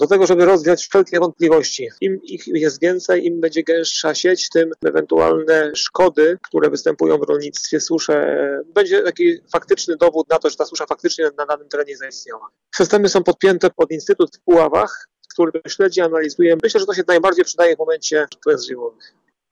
O znaczeniu stacji mówi Marcin Łepeciński, wicemarszałek województwa zachodniopomorskiego: „ Stacja meteorologiczna ma na celu rozwiać wszelkie wątpliwości dotyczące występowania zjawisk takich jak susza.